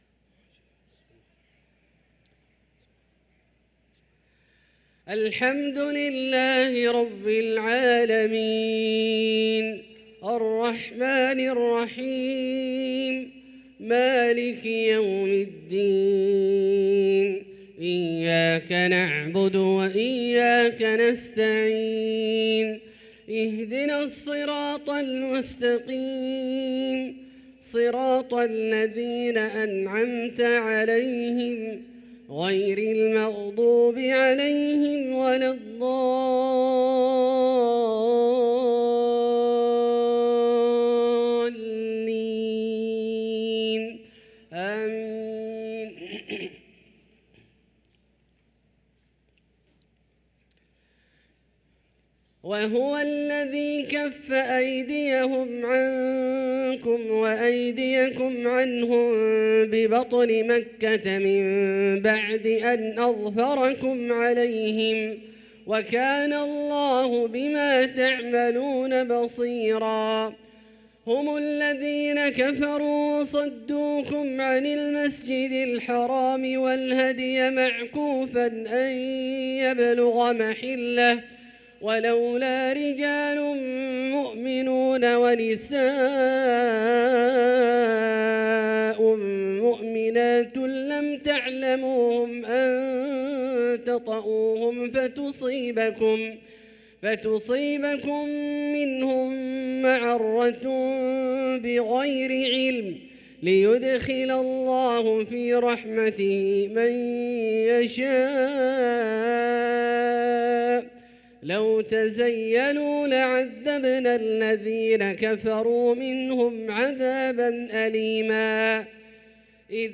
صلاة العشاء للقارئ عبدالله الجهني 25 جمادي الأول 1445 هـ
تِلَاوَات الْحَرَمَيْن .